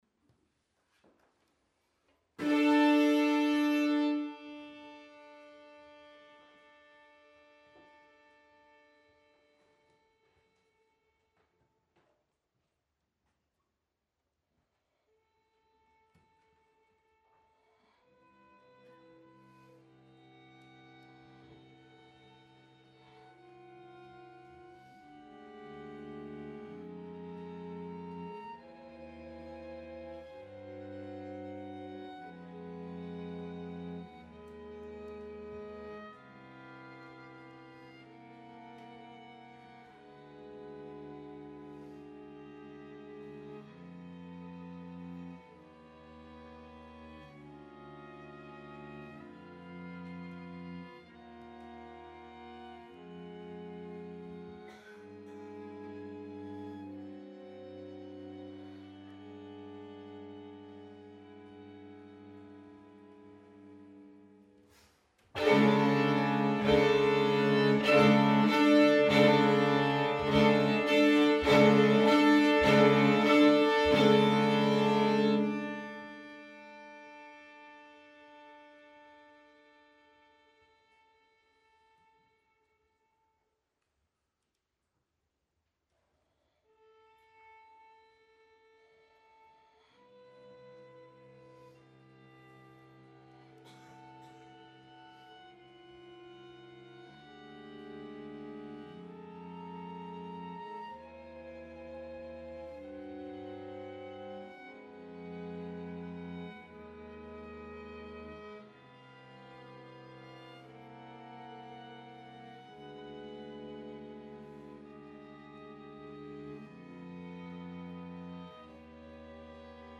Venue: Bantry Library
Instrumentation Category:String Quartet
violins
viola
cello